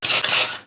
Spray de ambientador del baño (2 toques)
pulverizador
spray
Sonidos: Hogar